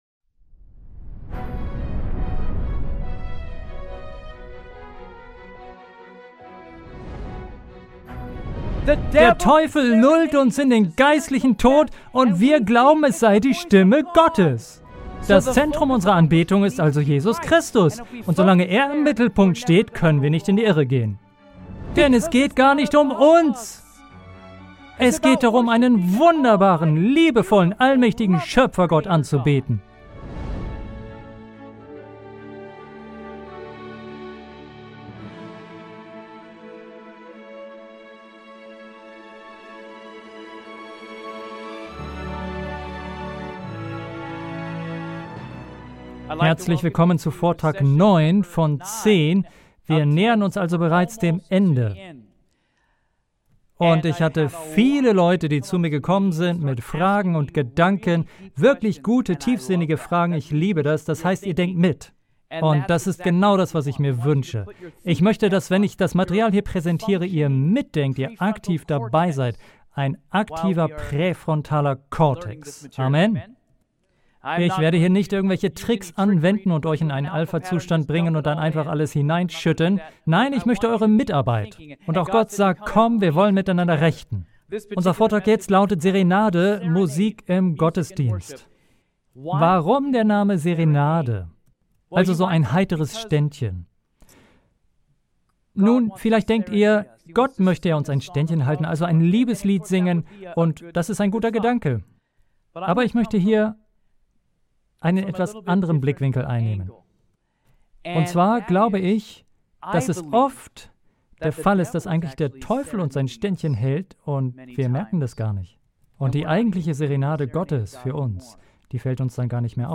Dann bist du bei diesem Seminar genau richtig.